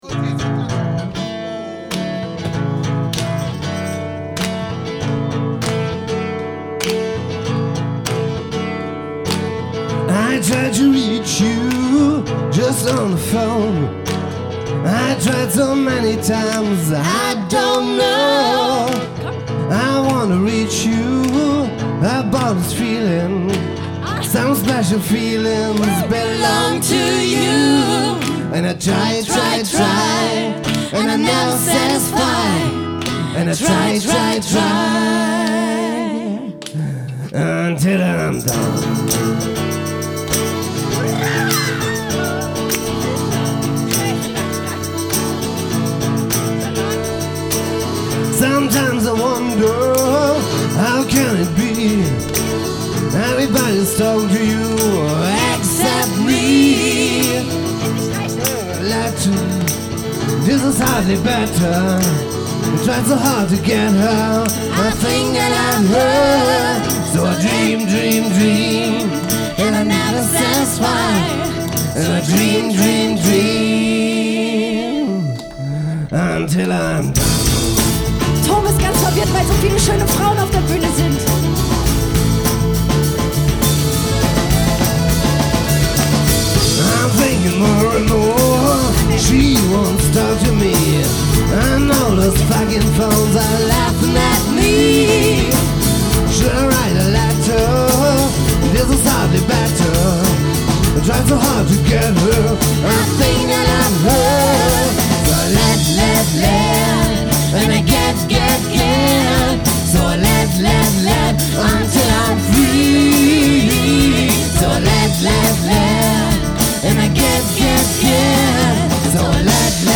Live, Fête de la Musique, Saarbrücken 2007